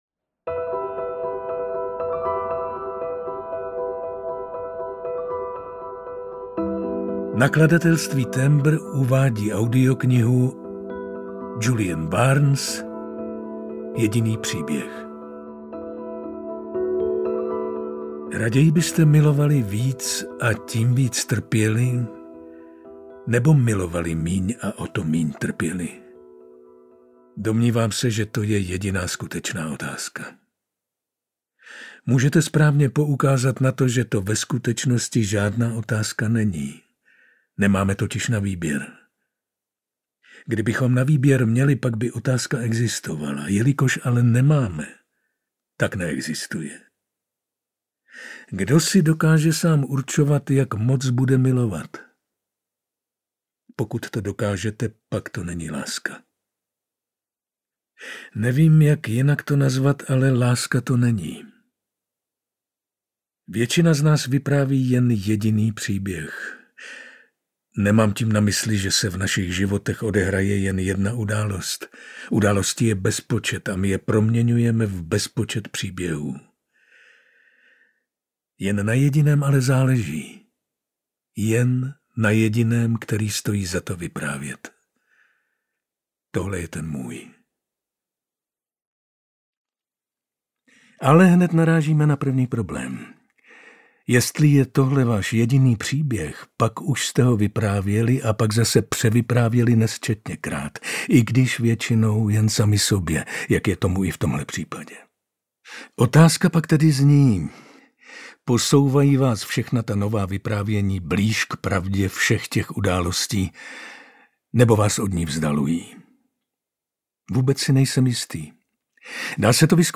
Jediný příběh audiokniha
Ukázka z knihy
• InterpretViktor Preiss